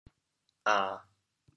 How to say the words 揞 in Teochew？